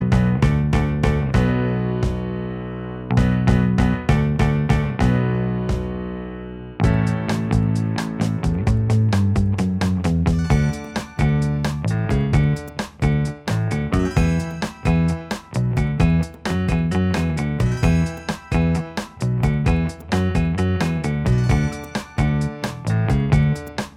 Minus Electric Guitar Pop (1960s) 2:42 Buy £1.50